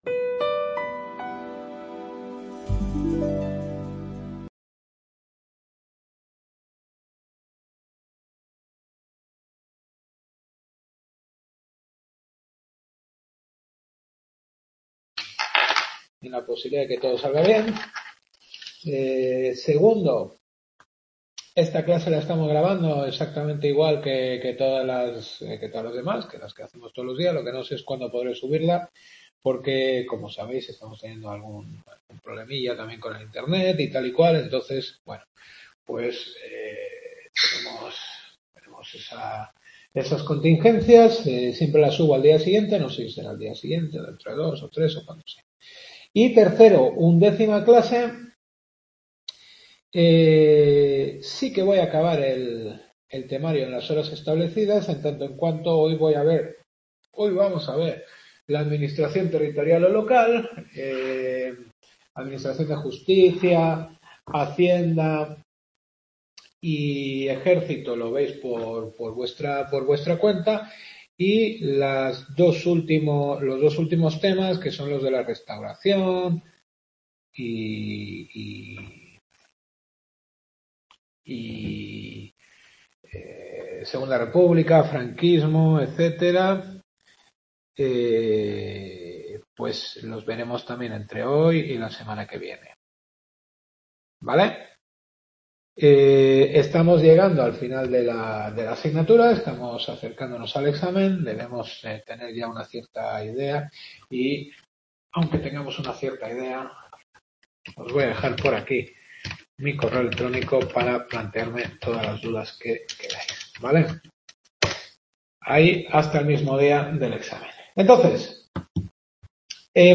Undécima Clase.